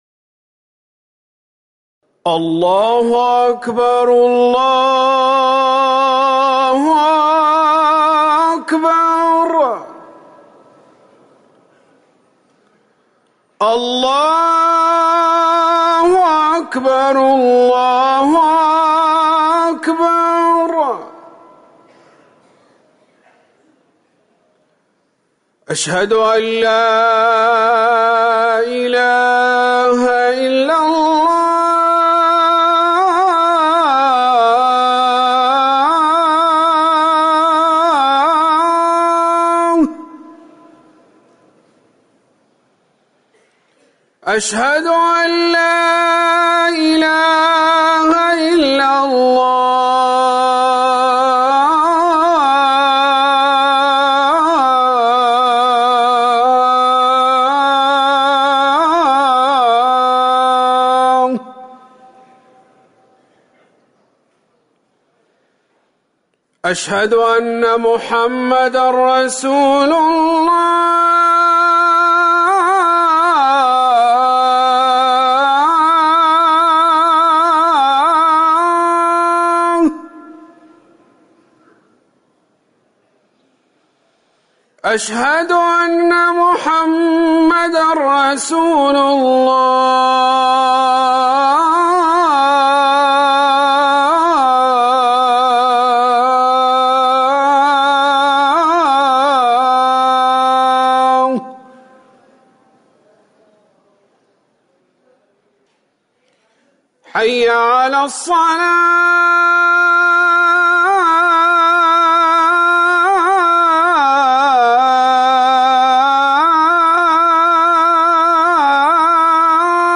أذان العصر
تاريخ النشر ٢٩ صفر ١٤٤١ هـ المكان: المسجد النبوي الشيخ